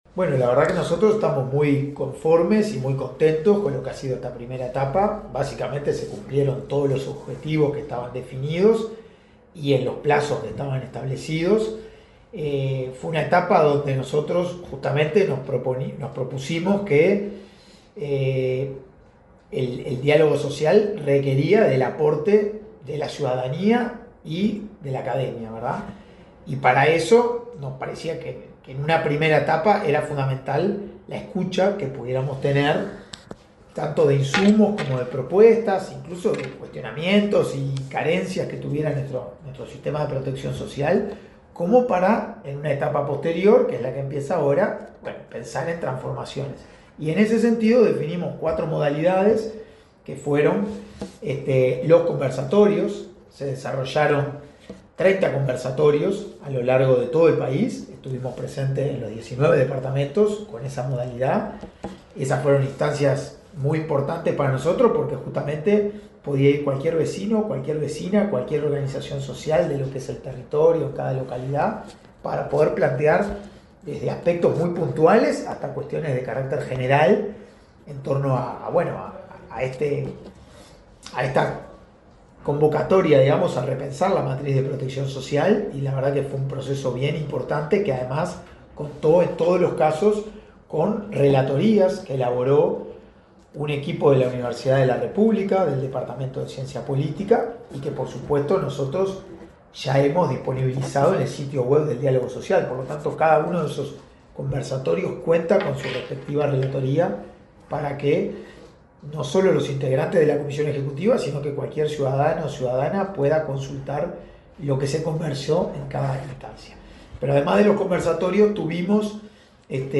Entrevista al coordinador de la comisión ejecutiva del Diálogo Social, Hugo Bai